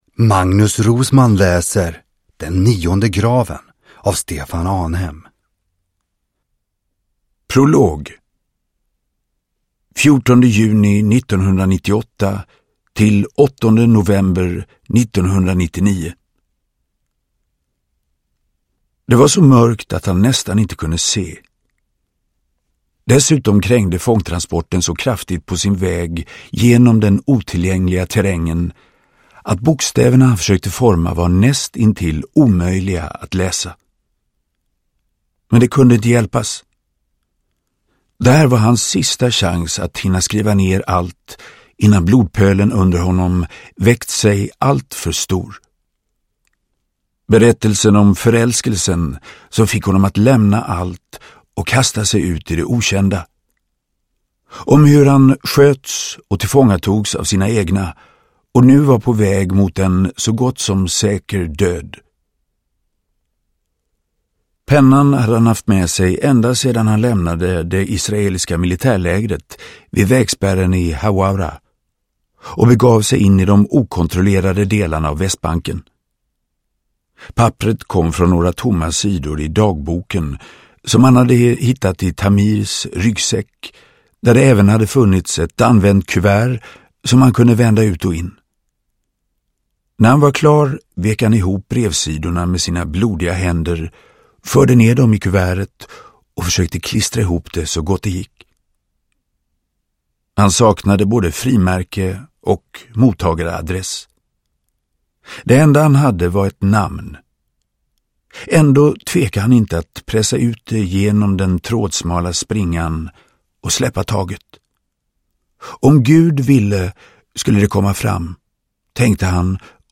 Den nionde graven – Ljudbok – Laddas ner
Uppläsare: Magnus Roosmann